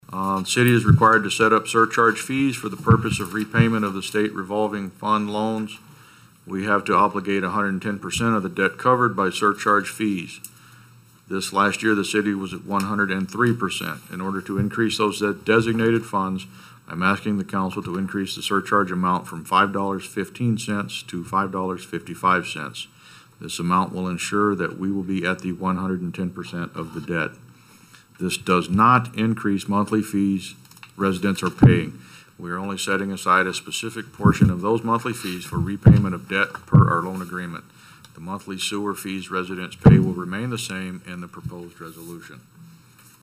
Resolution 21-09, setting fees for sanitary sewer rates, was also approved.  Mayor Gene Cox read the details.